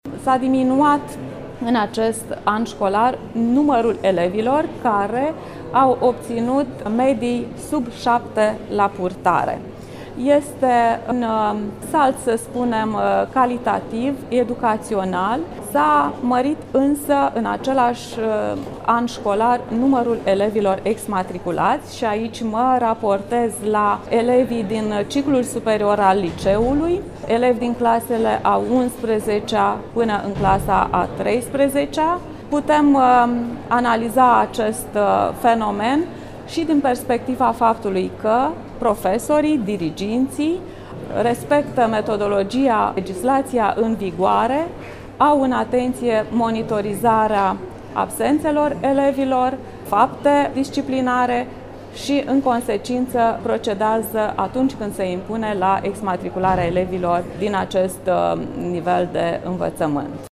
În judeţul Iași, a crescut numărul elevilor care sunt exmatriculați a precizat, astăzi, inspectorul școlar general la ISJ Iași, Genoveva Farcaș, în deschiderea Consfătuirii anuale a directorilor din unitățile de învățământ preuniversitar.